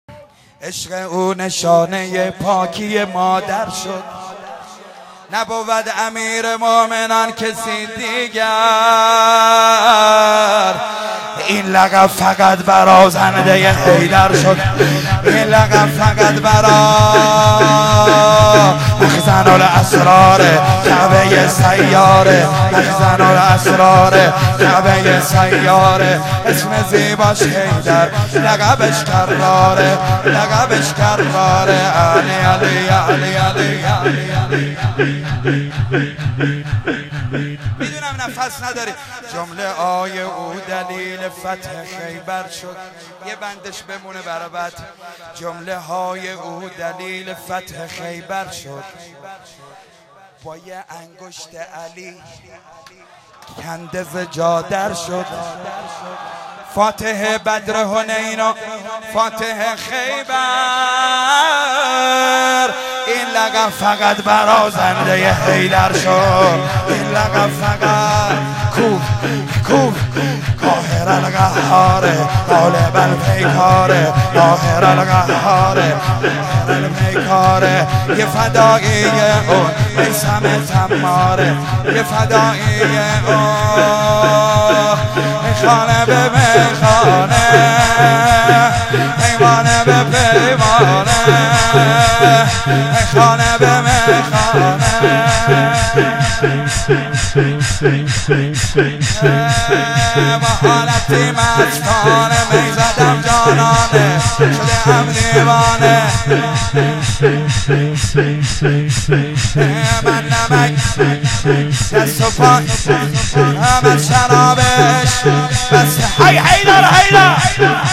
فایل های مدح وسینه زنی
7-شور امیرالمومنین